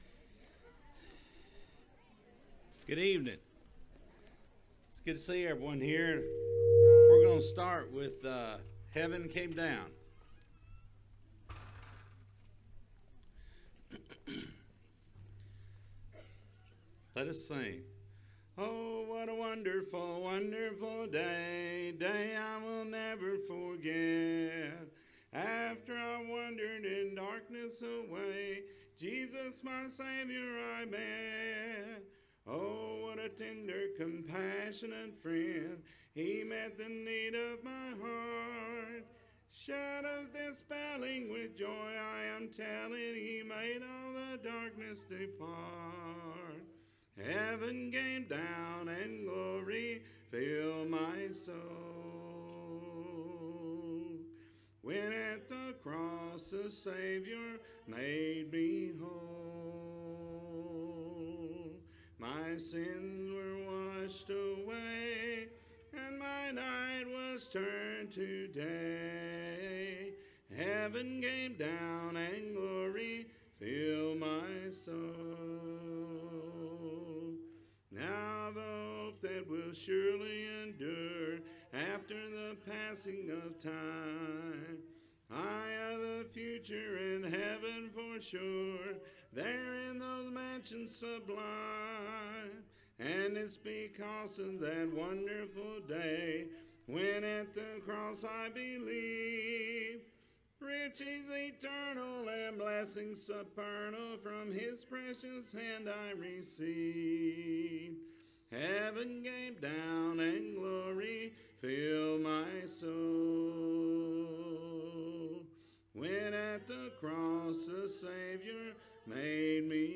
Matthew 7:7, English Standard Version Series: Sunday PM Service